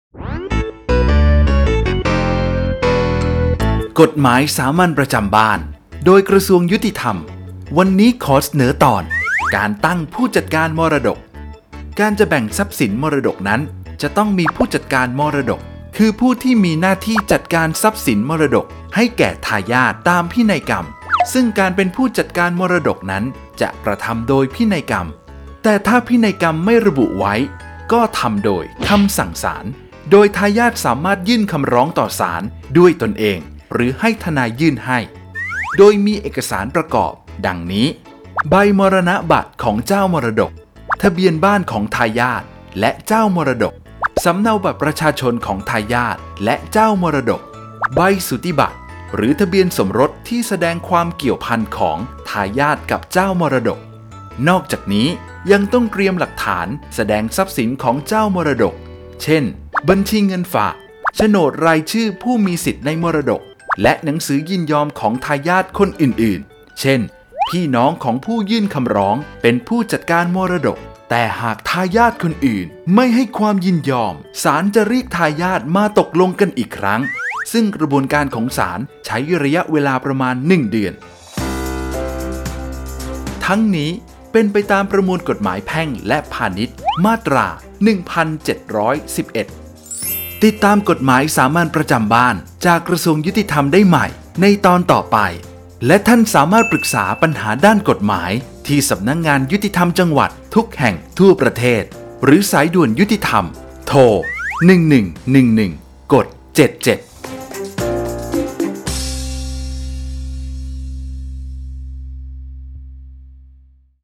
กฎหมายสามัญประจำบ้าน ฉบับภาษาท้องถิ่น ภาคกลาง ตอนการตั้งผู้จัดการมรดก
ลักษณะของสื่อ :   บรรยาย, คลิปเสียง